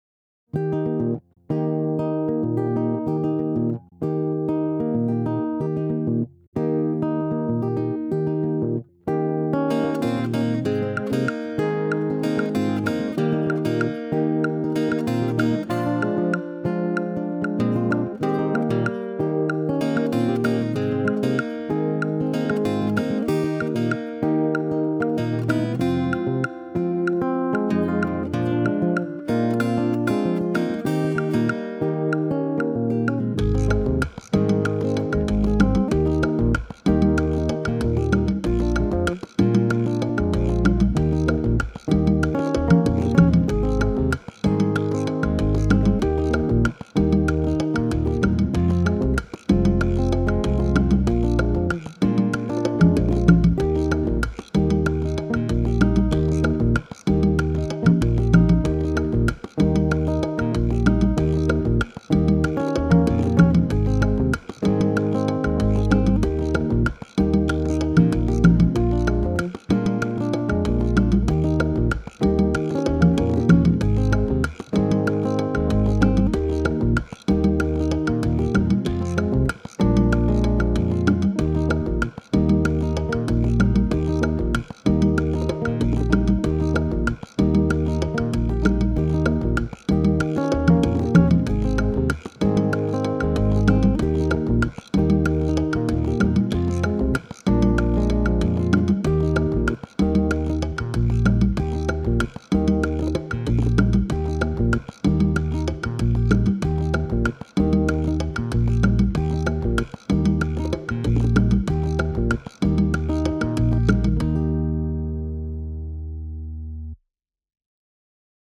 versión instrumental